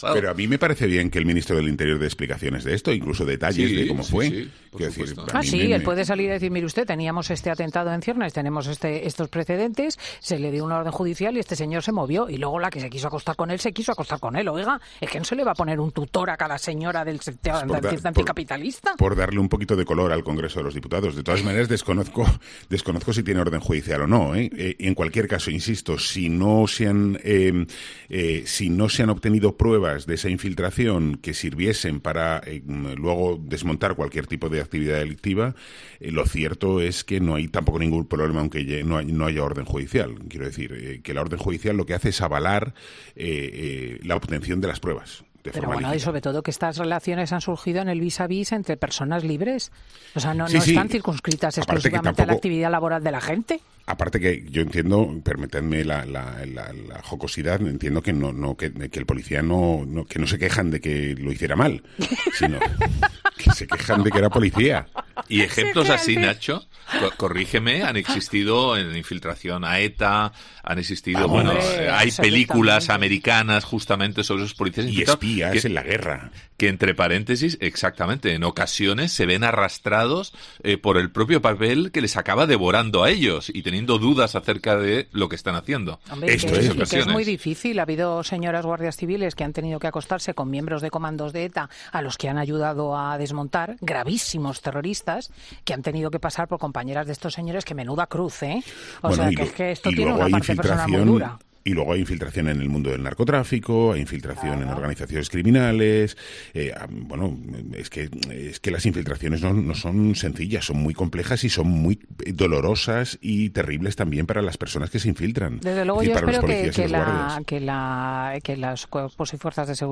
Ha sido entonces cuando se ha desatado la risa de la directora del programa, que no daba crédito a lo que estaba escuchando de su colaborador.